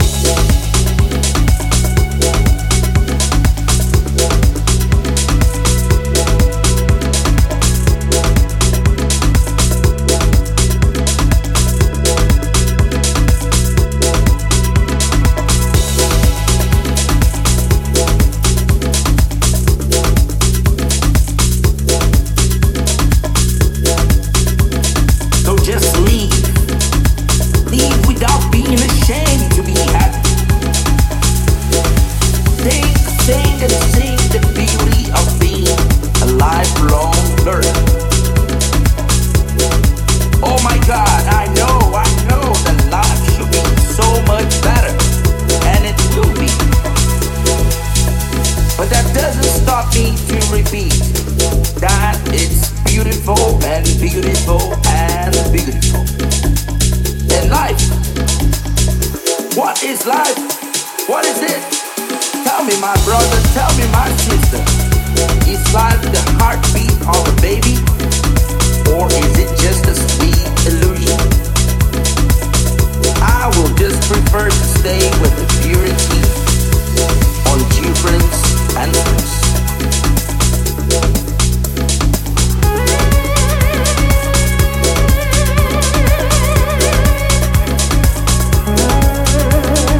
a percussive roller
spoken word